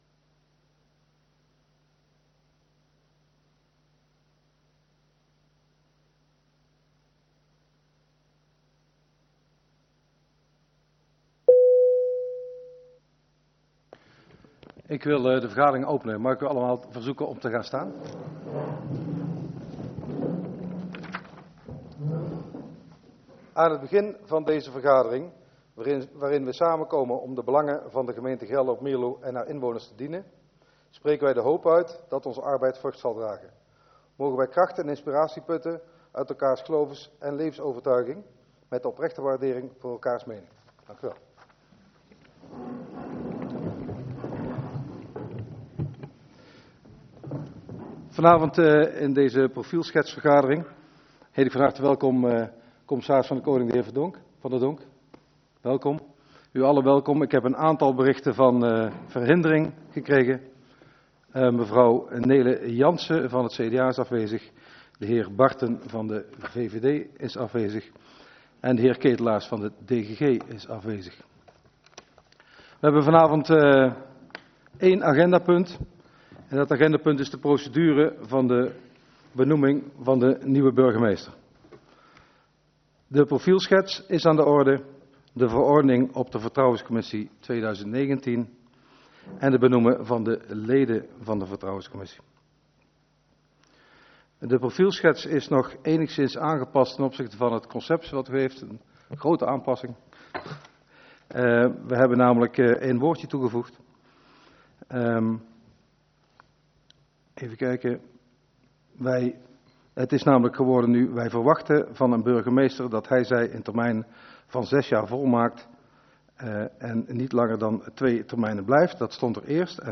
Agenda GeldropMierlo - Raadsvergadering dinsdag 11 juni 2019 19:30 - 20:30 - iBabs Publieksportaal